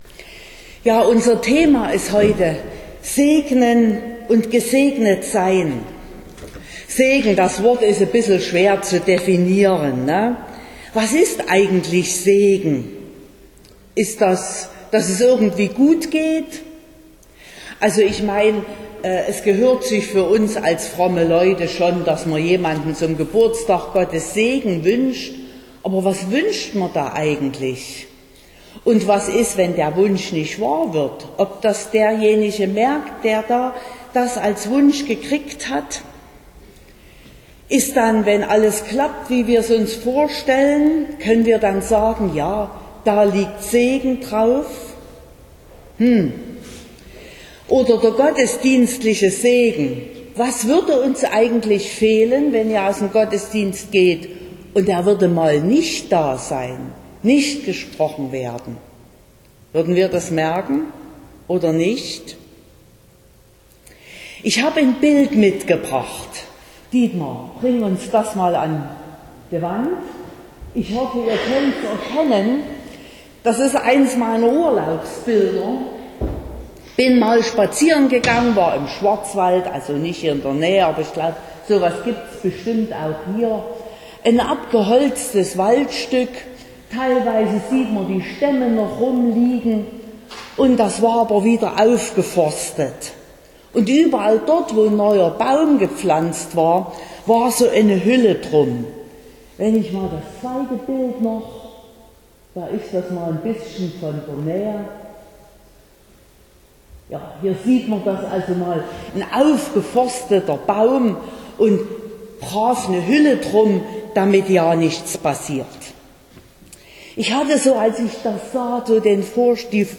25.06.2023 – Mehr-Licht-Gottesdienst
Predigt (Audio): 2023-06-25_Segnen_und_gesegnet_werden.mp3 (32,7 MB)